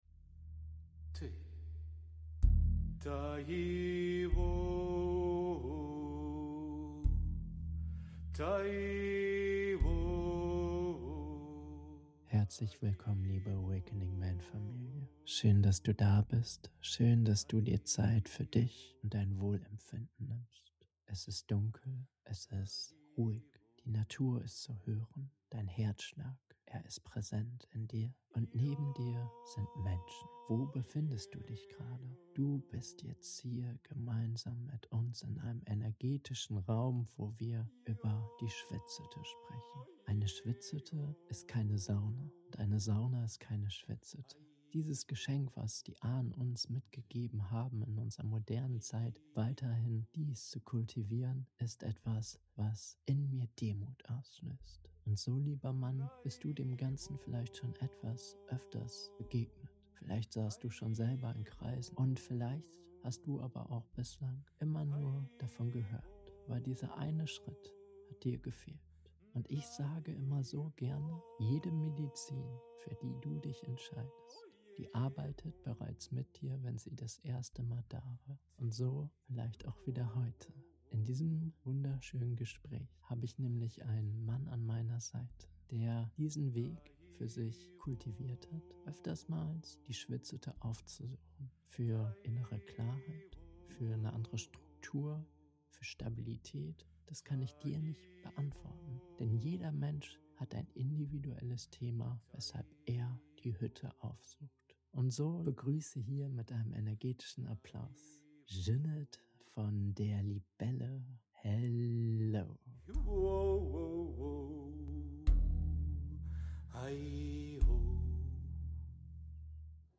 Die Medizin der Schwitzhütte - Interview